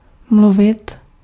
Ääntäminen
Synonyymit hovořit kecat mlít Ääntäminen : IPA: [mlʊvɪt] Haettu sana löytyi näillä lähdekielillä: tšekki Käännös Ääninäyte Verbit 1. speak US UK Esimerkit Mluvili spolu velmi dlouho.